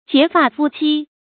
結發夫妻 注音： ㄐㄧㄝ ˊ ㄈㄚˋ ㄈㄨ ㄑㄧ 讀音讀法： 意思解釋： 結發：束發；指初成年。